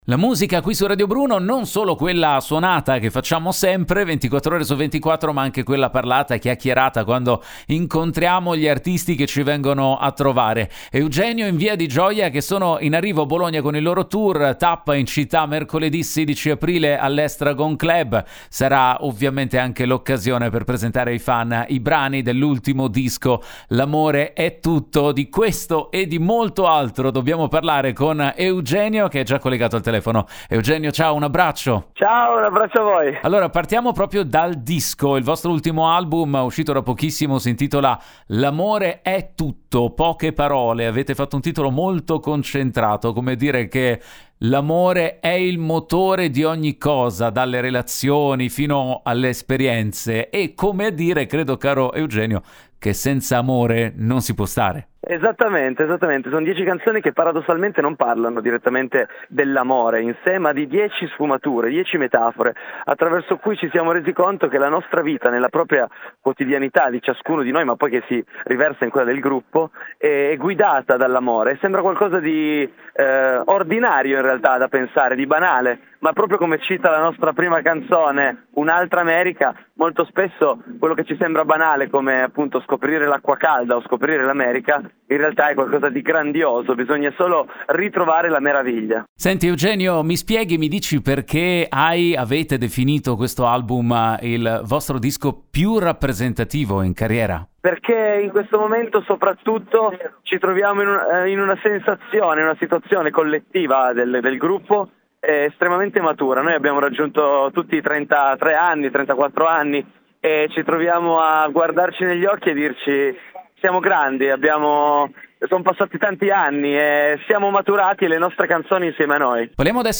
ha raggiunto al telefono